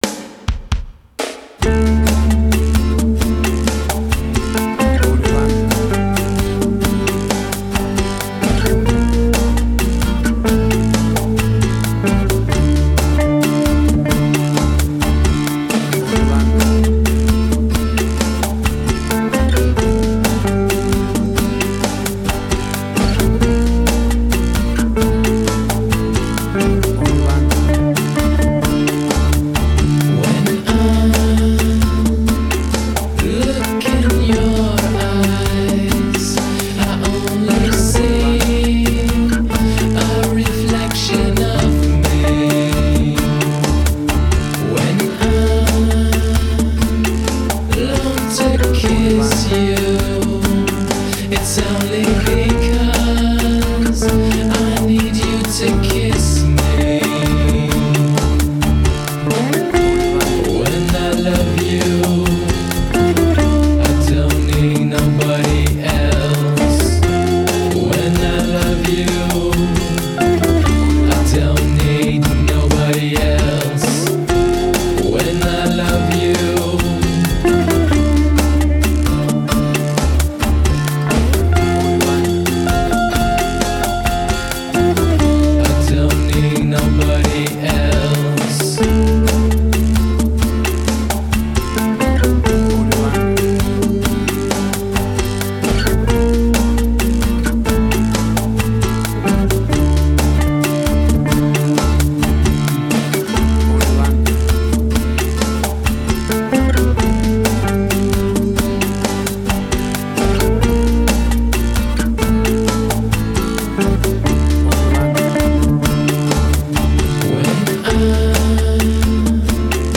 Latin Fusion Orchestral Hybrid
Tempo (BPM): 133